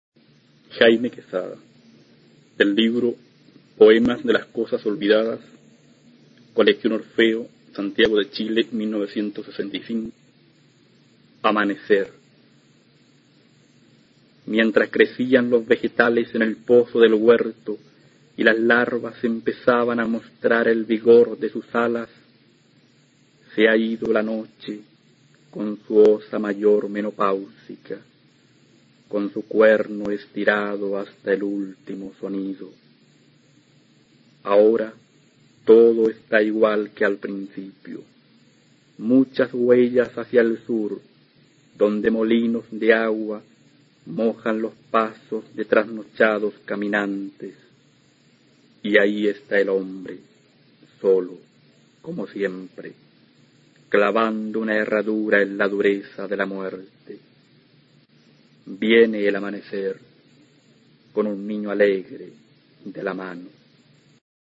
A continuación se puede escuchar a Jaime Quezada, autor chileno de la Generación del 60, recitando su poema Amanecer, del libro "Poemas de las cosas olvidadas" (1965).